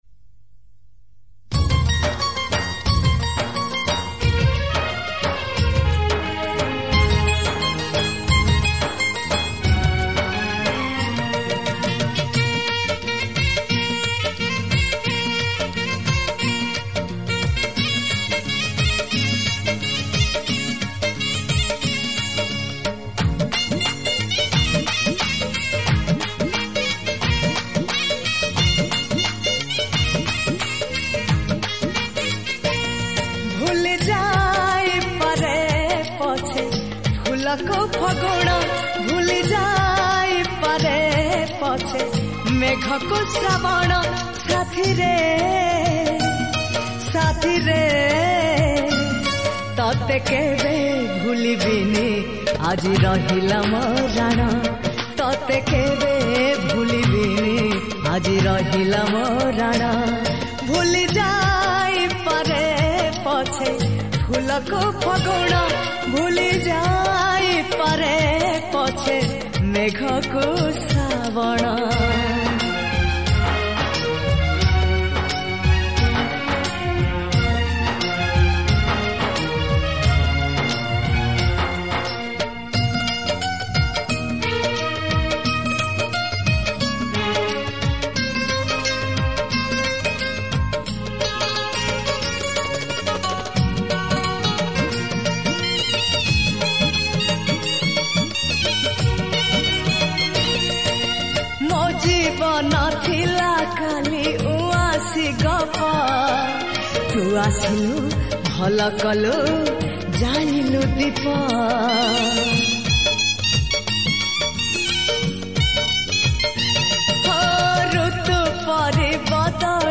Romantic Song